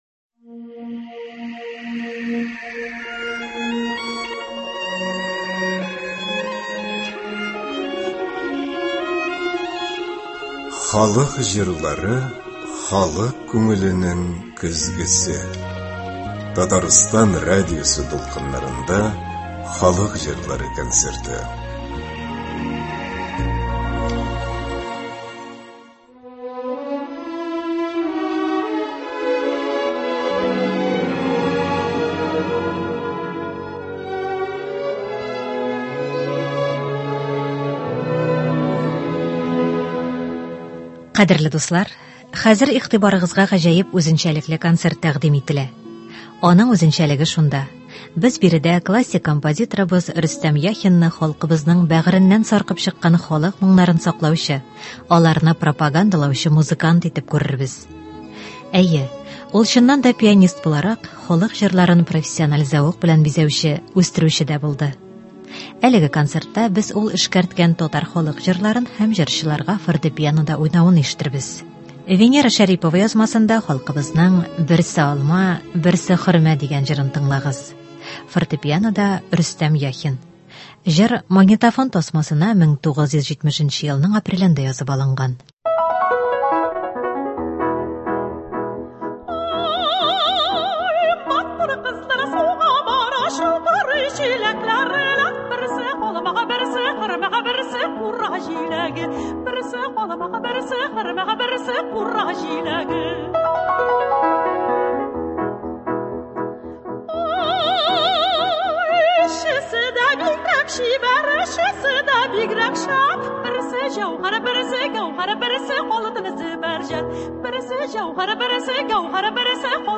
Әйе, ул, чыннан да, пианист буларак, халык җырларын профессиональ зәвык белән бизәүче, үстерүче дә булды. Әлеге концертта без ул эшкәрткән татар халык җырларын һәм җырчыларга фортепианода уйнавын ишетербез.